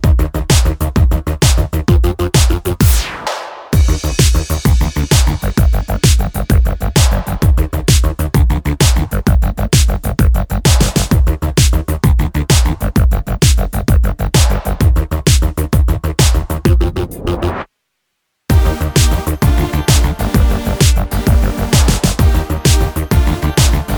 Minus Bass And Clavi Pop (2010s) 3:36 Buy £1.50